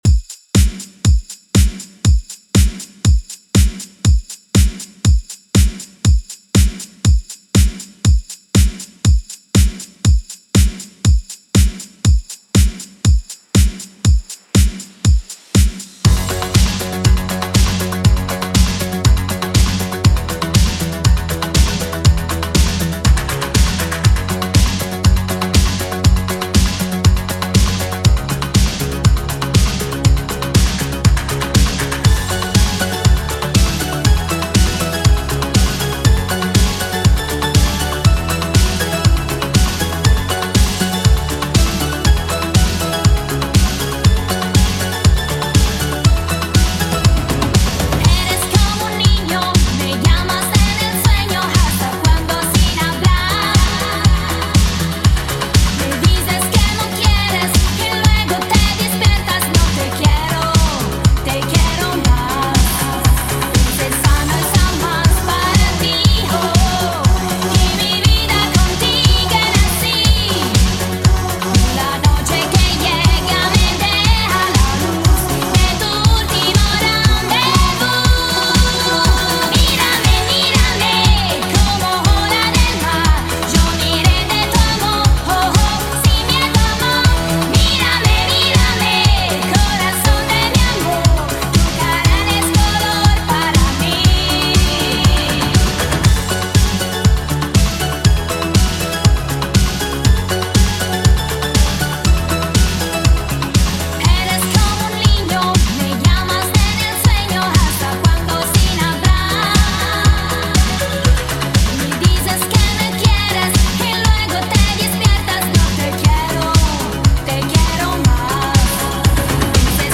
BPM: 120 Time